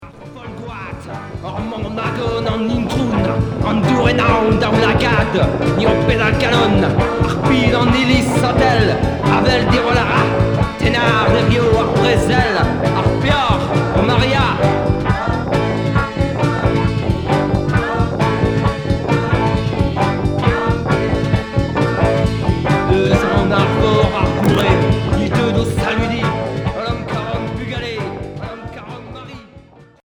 Folk rock progressif